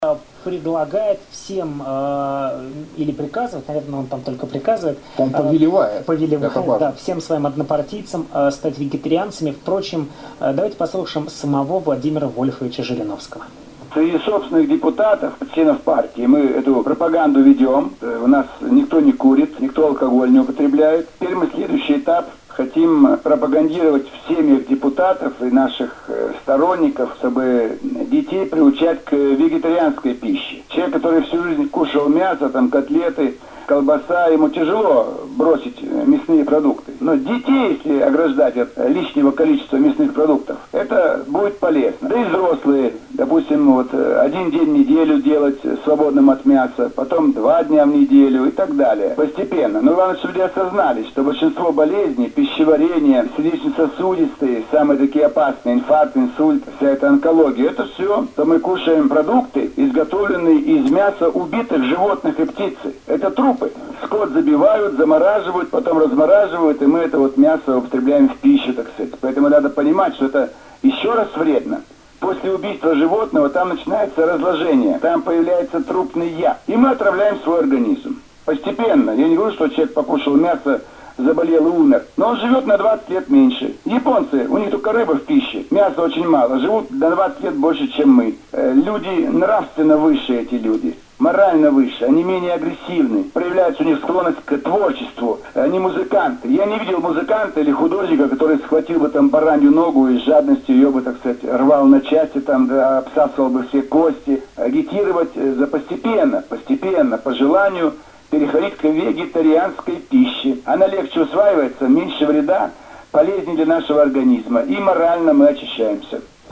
И морально мы очищаемся, - сказал Жириновский в эфире радио «Комсомольская правда».
zhirinovsky_for_vegetarian_radio-kp.mp3